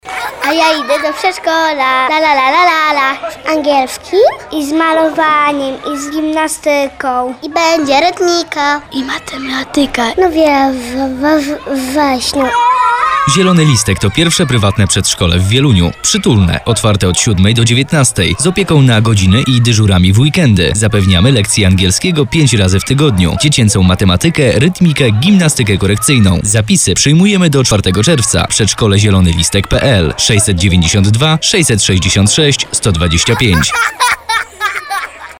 Przedszkole Zielony Listek - spot radiowy | branding
Brief: lekko, inaczej niż monolog lektora, ale z podkreśleniem zajęć dodatkowych Target